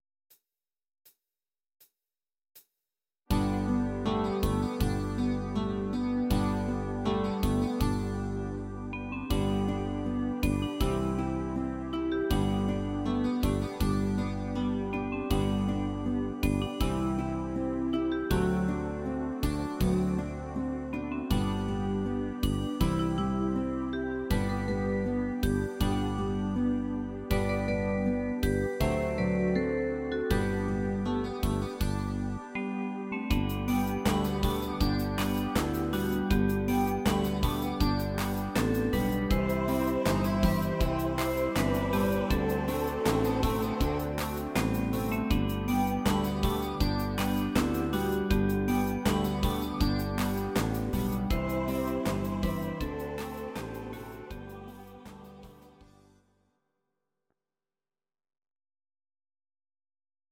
Audio Recordings based on Midi-files
German, 1970s